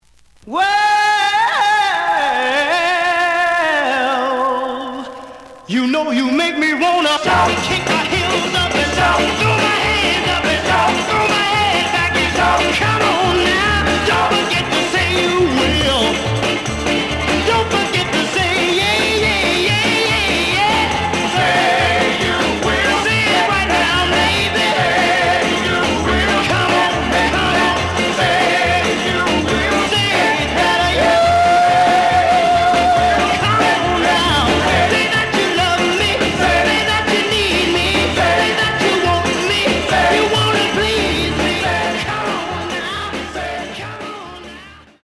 試聴は実際のレコードから録音しています。
●Genre: Rhythm And Blues / Rock 'n' Roll
EX-, VG+ → 傷、ノイズが多少あるが、おおむね良い。